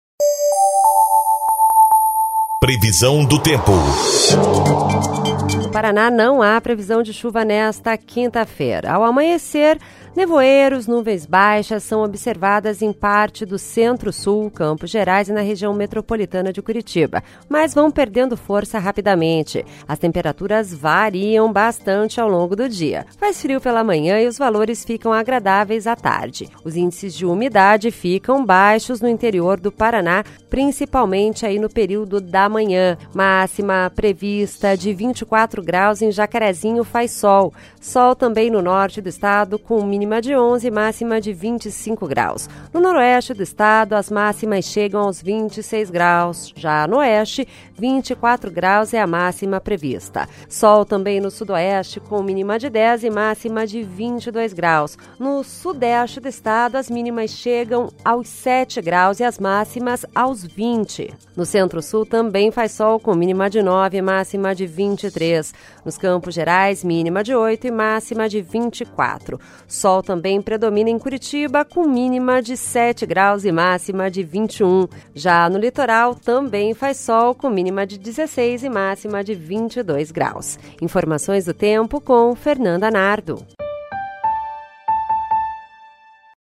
Previsão do tempo (18/05)